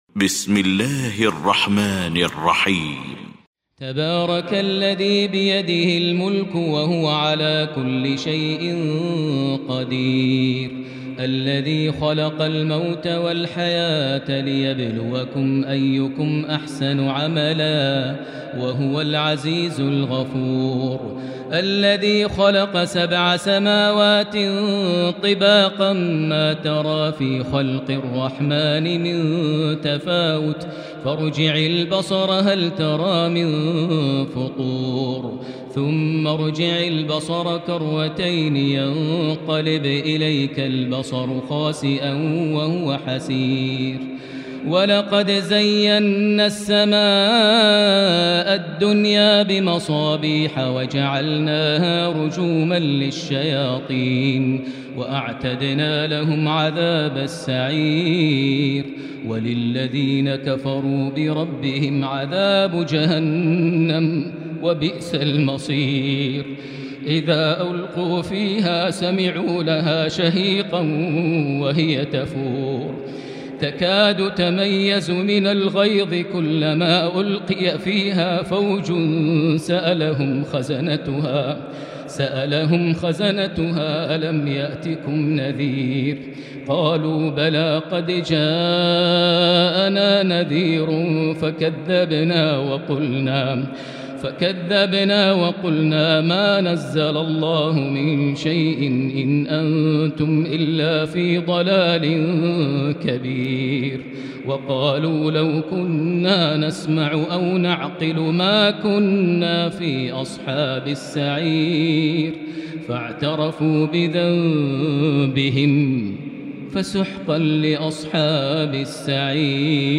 المكان: المسجد الحرام الشيخ: فضيلة الشيخ ماهر المعيقلي فضيلة الشيخ ماهر المعيقلي الملك